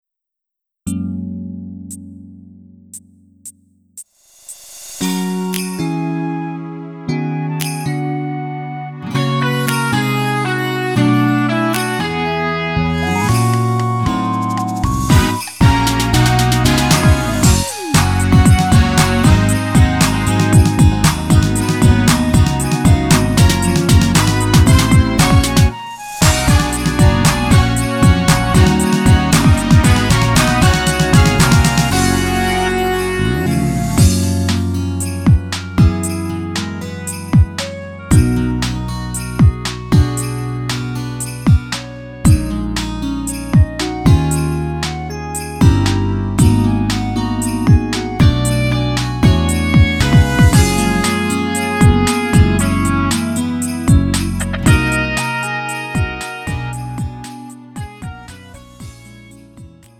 음정 -1키 3:13
장르 구분 Lite MR